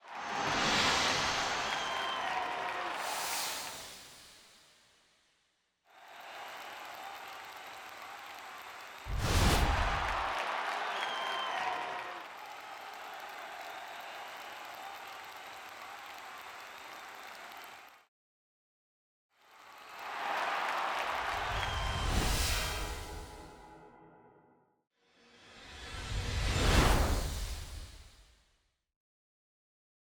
HumanNature_MomentinTime_ST_SFX.wav